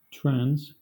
Ääntäminen
Ääntäminen Southern England Tuntematon aksentti: IPA : /tɹænz/ Haettu sana löytyi näillä lähdekielillä: englanti Käännöksiä ei löytynyt valitulle kohdekielelle.